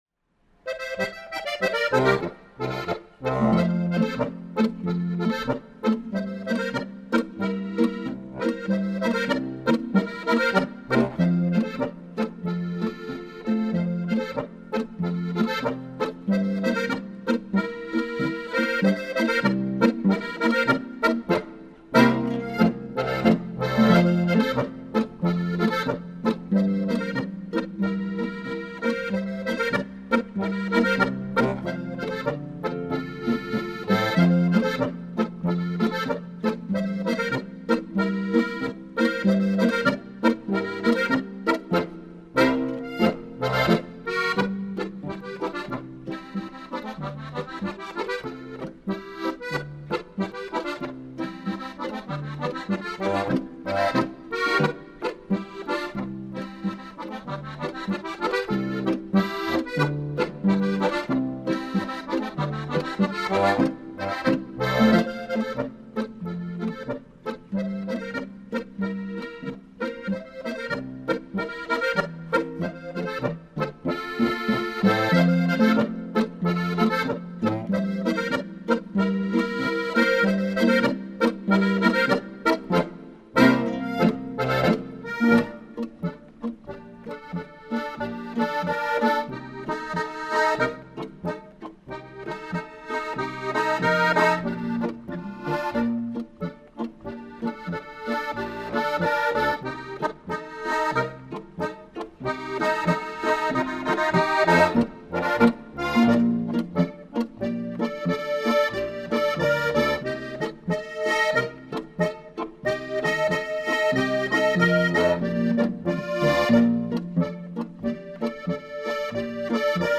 Ein beliebter Boarischer!